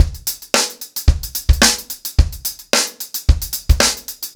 TupidCow-110BPM.27.wav